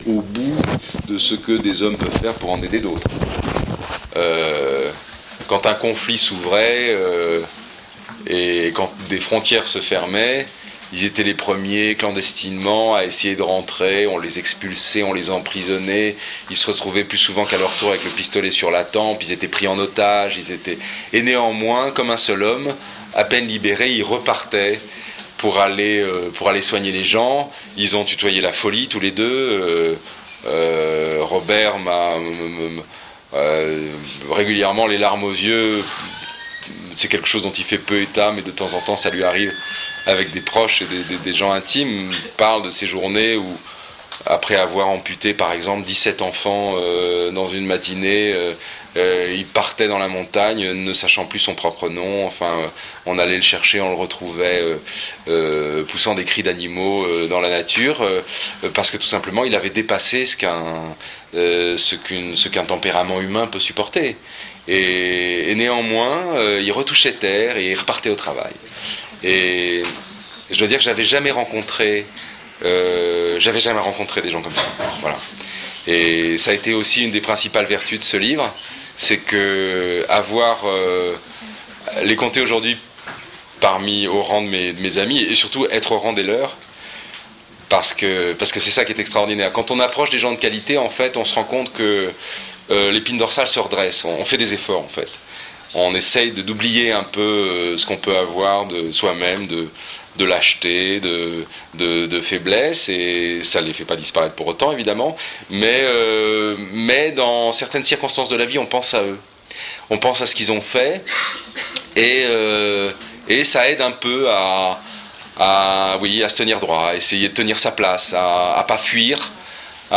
Extraits d'une conférence tenue par Emmanuel Guibert au Lycée Français de Vienne